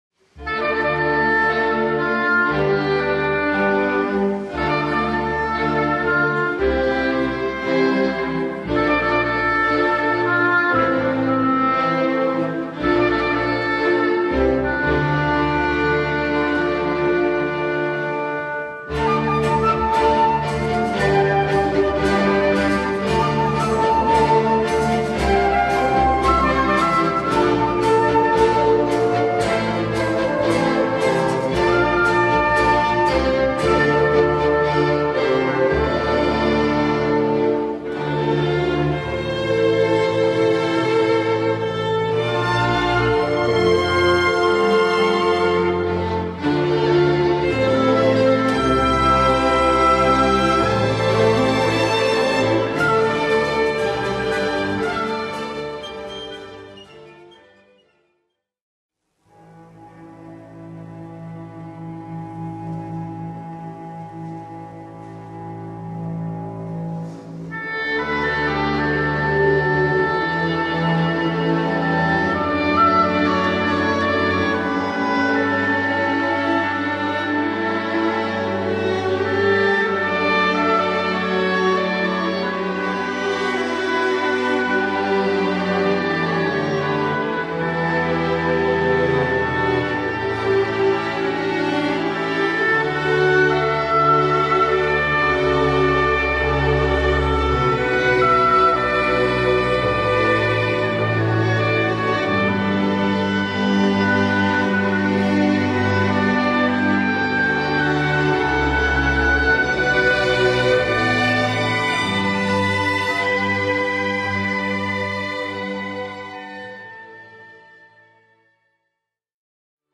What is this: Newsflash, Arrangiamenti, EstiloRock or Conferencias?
Arrangiamenti